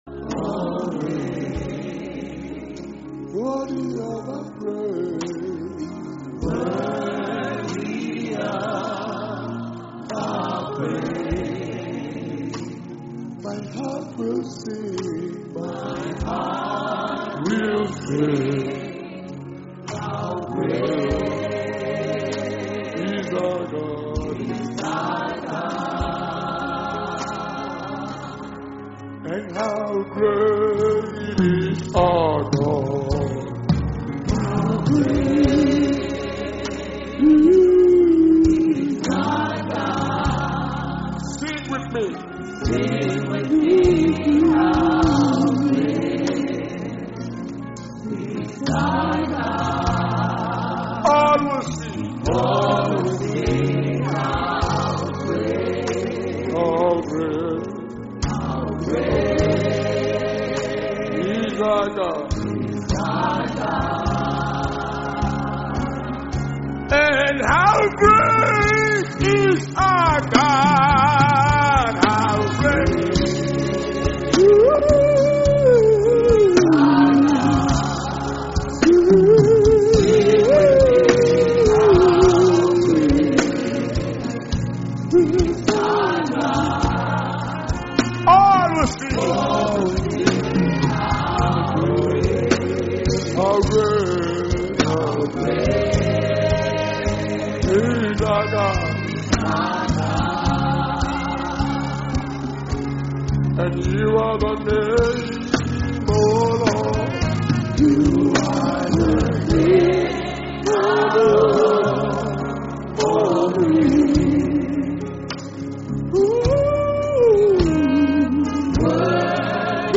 Destiny Recovery Convention May 30th 2021 Thanksgiving And Testimony Service Message